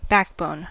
Ääntäminen
US : IPA : [ˈbæk.boʊn]